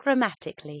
Hur uttalas ordet grammatisk ? [gra'matisk]